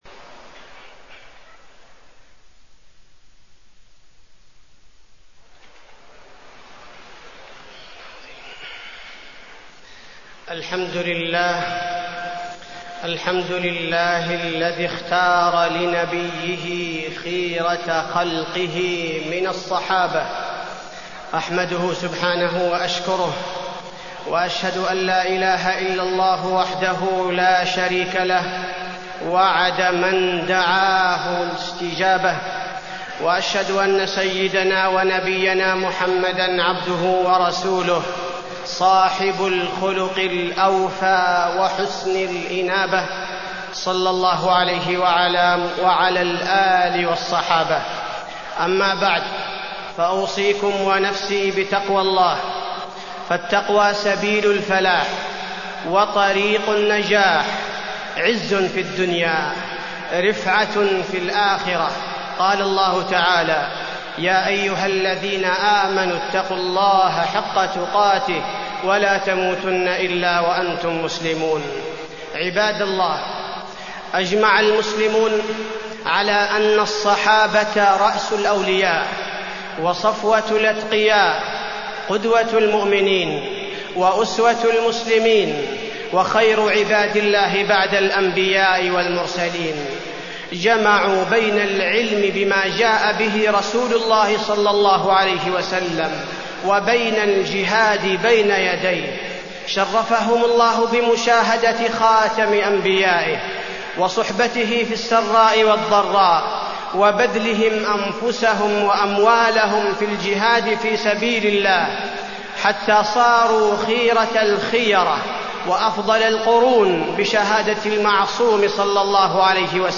تاريخ النشر ٧ شعبان ١٤٢٤ هـ المكان: المسجد النبوي الشيخ: فضيلة الشيخ عبدالباري الثبيتي فضيلة الشيخ عبدالباري الثبيتي فضائل الصحابة